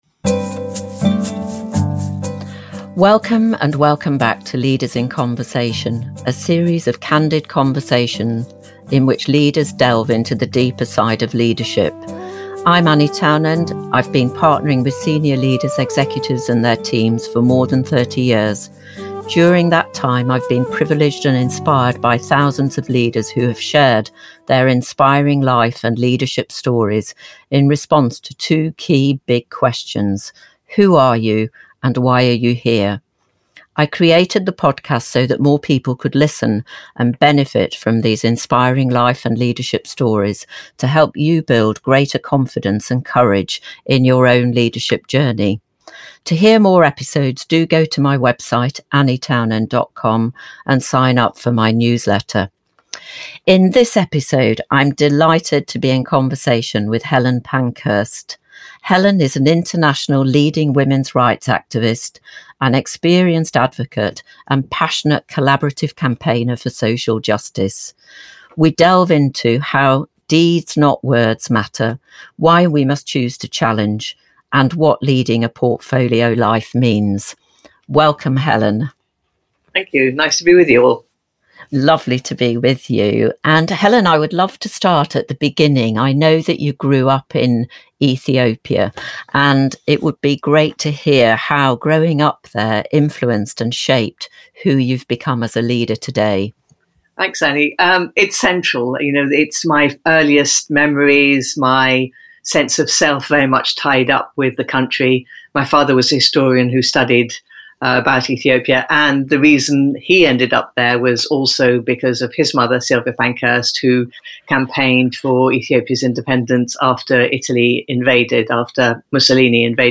Deeds not Words. A conversation with Helen Pankhurst. Insights from a leading women's rights activist
In this episode I am in conversation with Helen Pankhurst an international leading women’s rights activist, an experienced advocate and passionate collaborative campaigner for social justice.